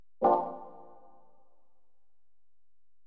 swaync: added notification sound effect
gran_turismo_menu_sound_effect.mp3